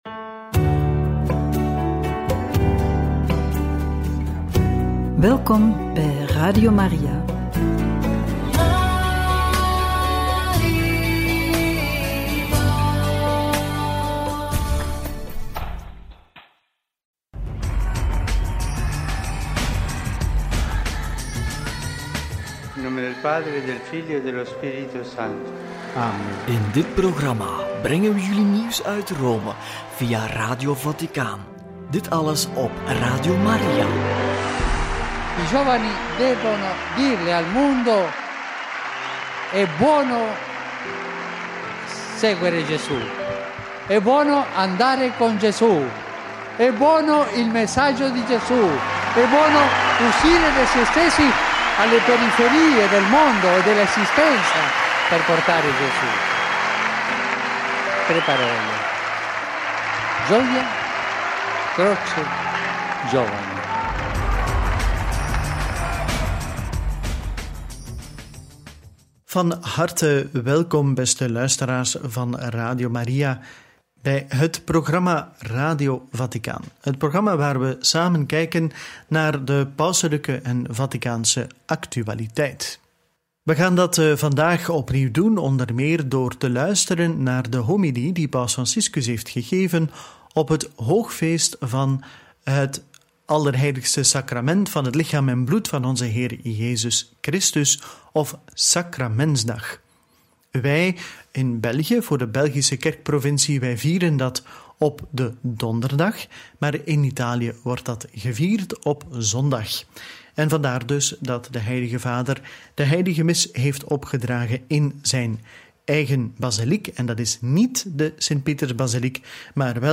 Homilie hoogfeest van Sacramentsdag – Boodschap voor de 110e Werelddag voor Migranten en Vluchtelingen – Radio Maria